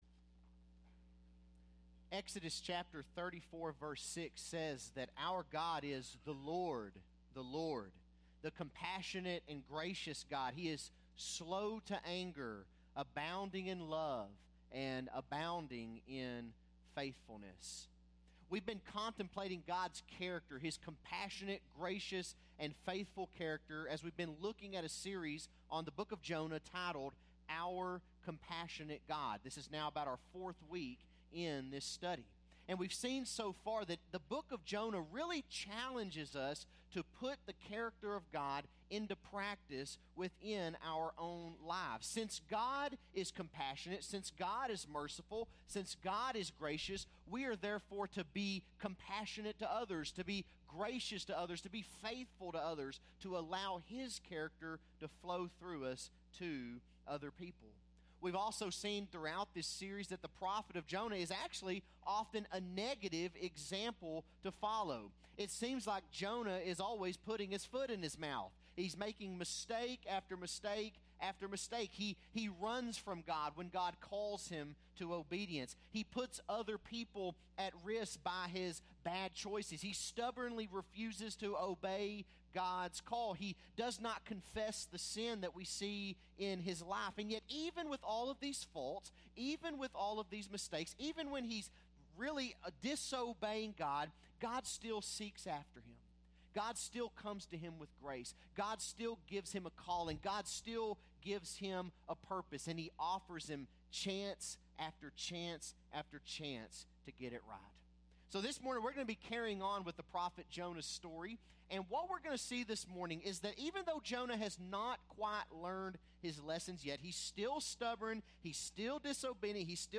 Bible Text: Jonah 3 | Preacher